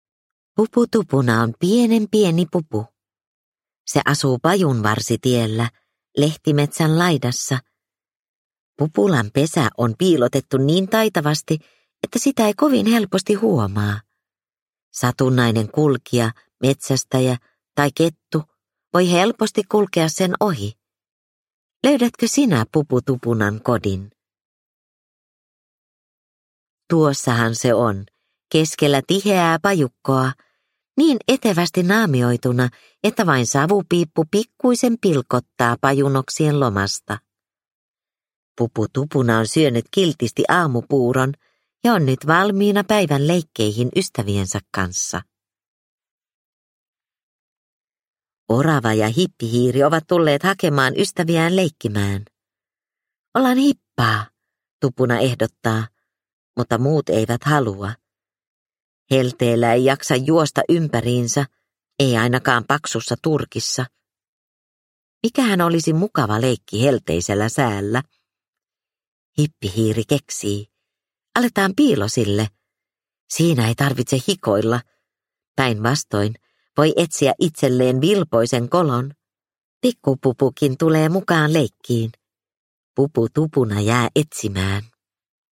Pupu Tupuna piilosilla – Ljudbok – Laddas ner